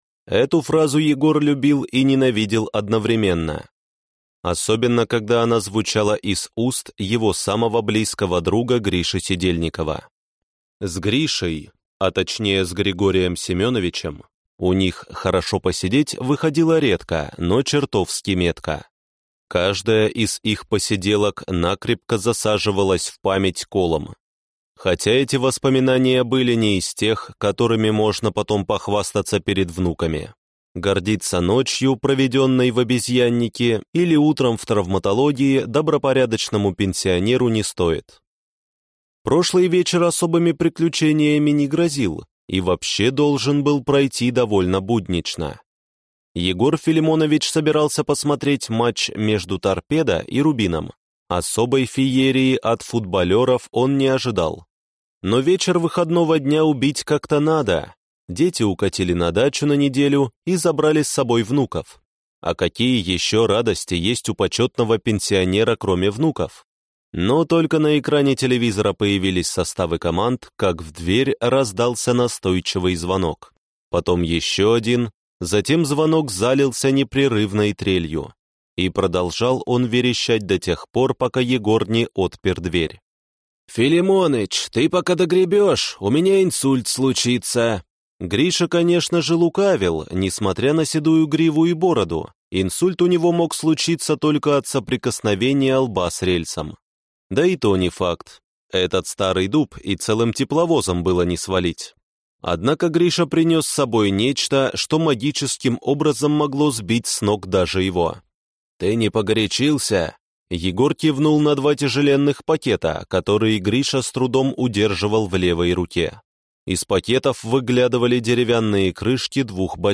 Аудиокнига Красная галактика. Книга 1 | Библиотека аудиокниг